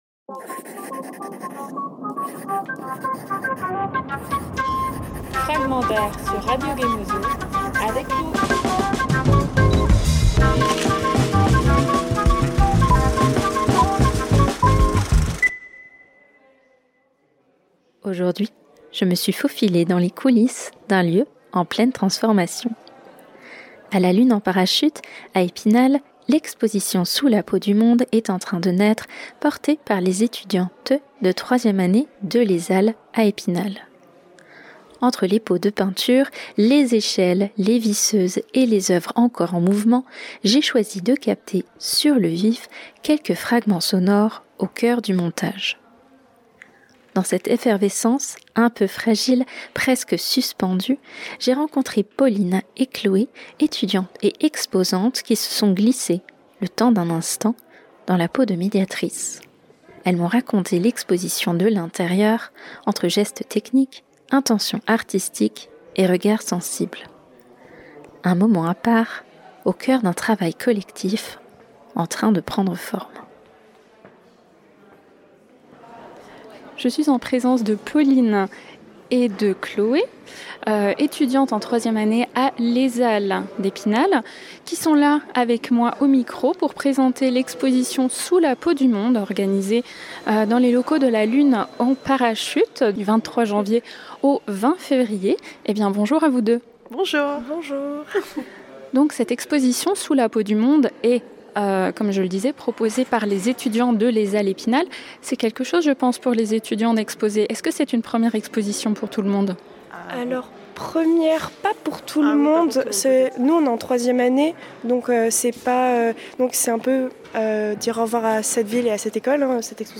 23 janvier 2026 - 14:30 - 397 vues Écouter le podcast Télécharger le podcast Entre les pots de peinture, les visseuses et les œuvres encore en mouvement, je me suis faufilée dans le montage de l’exposition Sous la peau du monde à La Lune en Parachute, à Épinal. Ce fragment sonore a été capté sur le vif, au cœur des gestes techniques, des corps au travail, des échanges improvisés et de la création collective en train de naître.